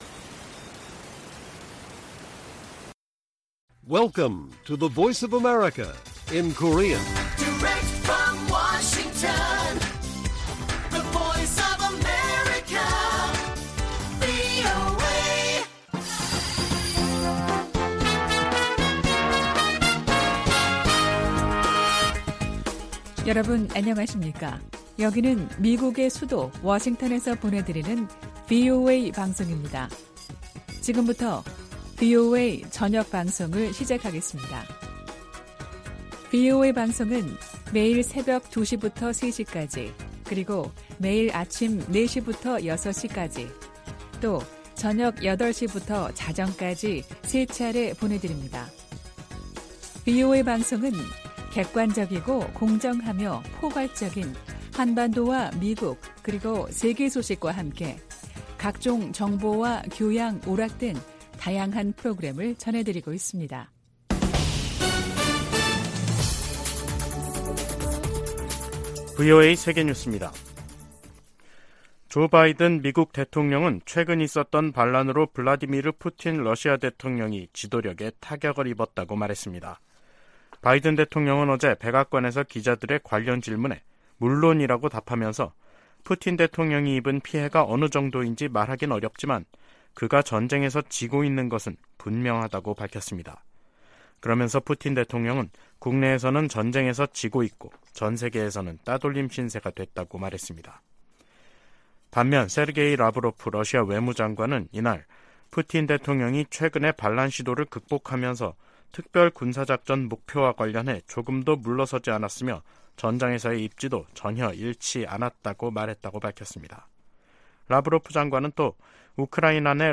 VOA 한국어 간판 뉴스 프로그램 '뉴스 투데이', 2023년 6월 29일 1부 방송입니다. 커트 캠벨 백악관 국가안보회의(NSC) 인도태평양 조정관은 미한일 정상회담에서 3국 협력 가능 방안을 모색할 것이라고 말했습니다. 북한은 인신매매를 정부 정책으로 삼고 있는 최악의 인신매매 국가라고 국무부 고위관리가 지적했습니다. 토니 블링컨 미 국무장관은 중국과 평화적 공존 방안을 모색해야 한다며, 이를 위해 동맹·파트너와 협력을 강화하고 있다고 밝혔습니다.